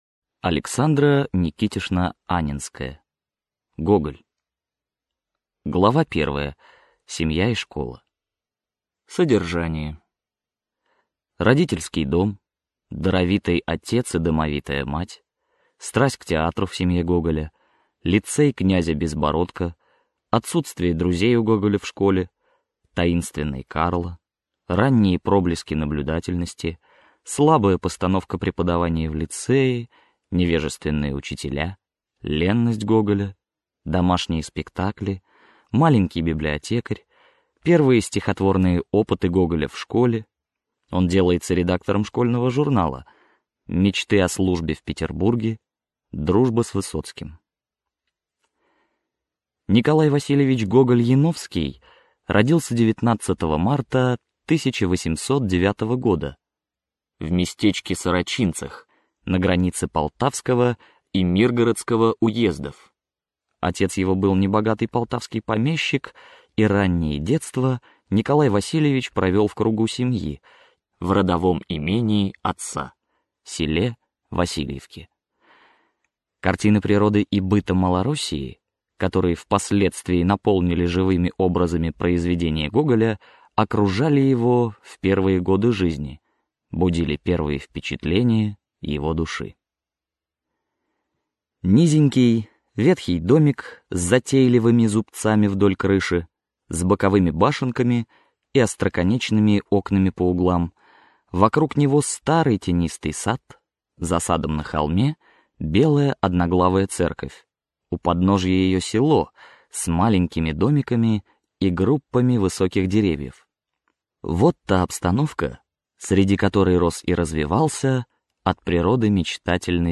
Аудиокнига Жизнь и творчество Николая Васильевича Гоголя | Библиотека аудиокниг